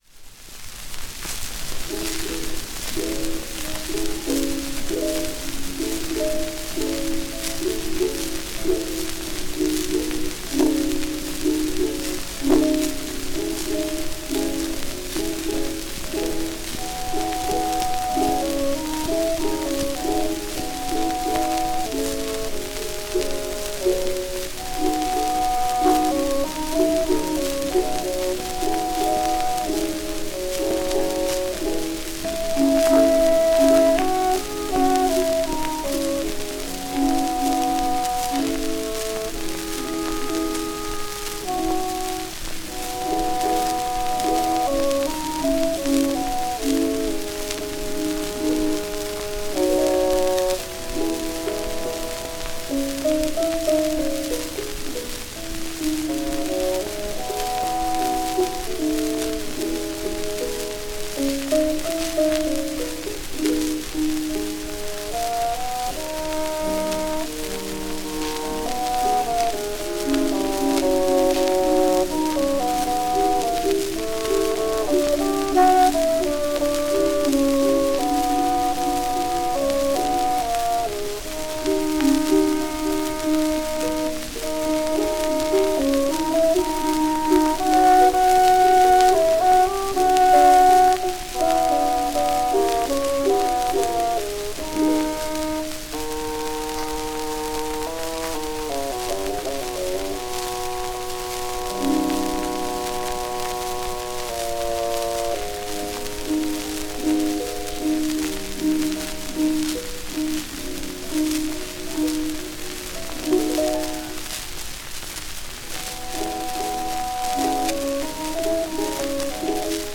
woodwind (bassoon) and harp duet